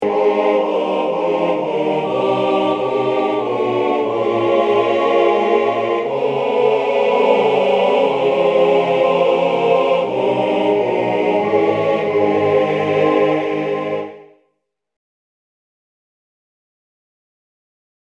Key written in: A♭ Major
How many parts: 4
Type: Other mixed
Tenor and lead alternate with the melody.
All Parts mix: